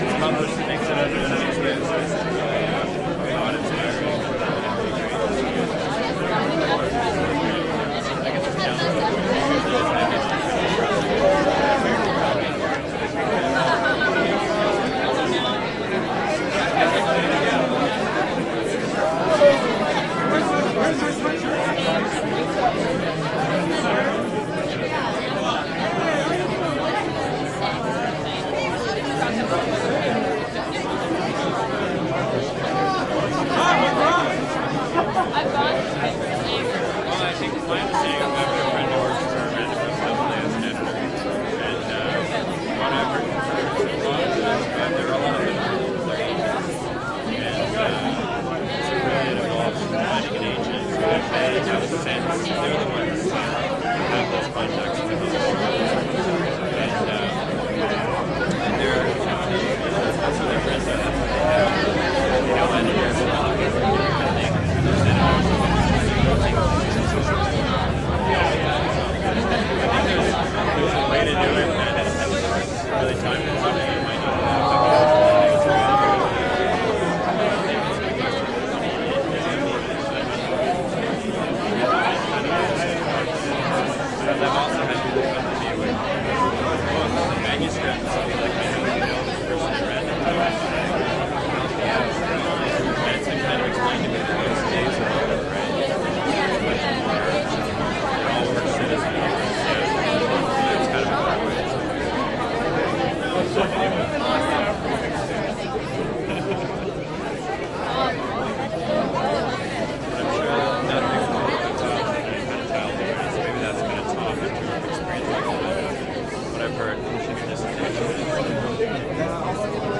随机的 " 人群中广泛的重度密集的瓦拉在人群中的电影首映式
描述：在人群电影首映的人群中等重度密集的walla.flac
Tag: FLAC 分机 首映 人群密集 沃拉